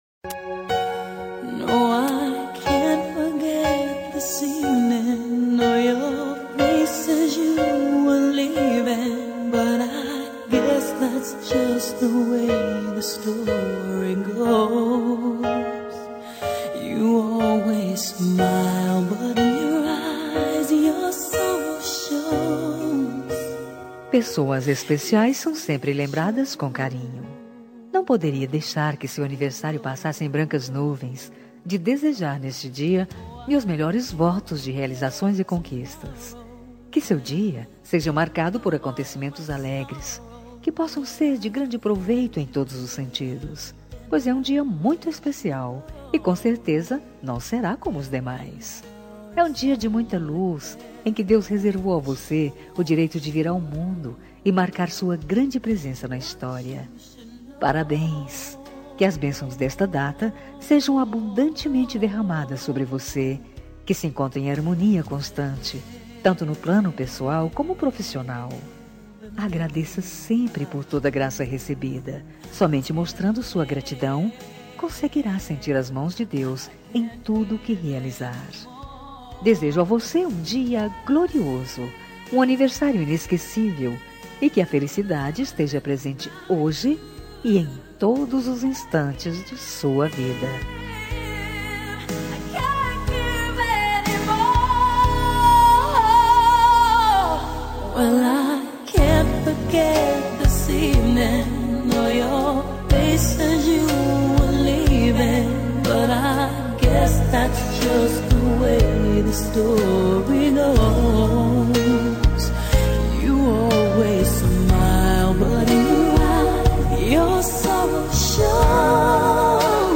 Aniversário de Ficante – Voz Feminina – Cód: 8876
aniv-ficante-fem-8876.m4a